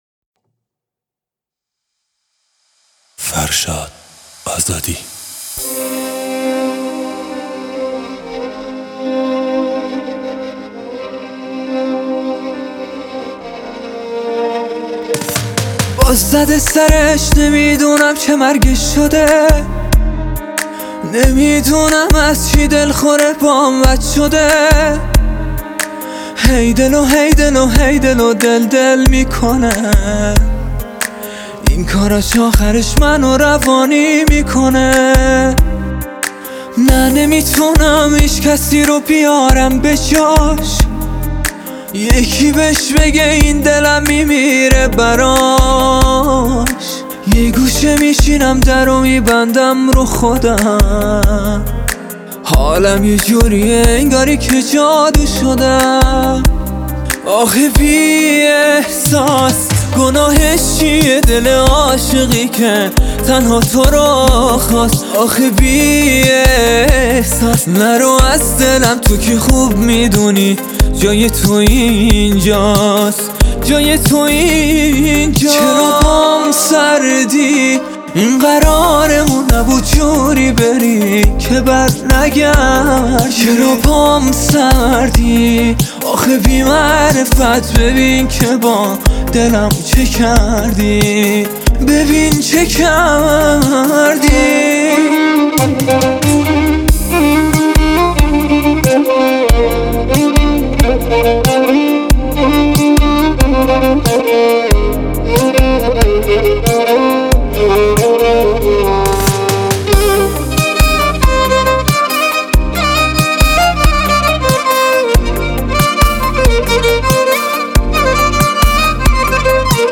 عاشقانه و شنیدنی